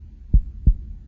Heartbeat
singlebeat.ogg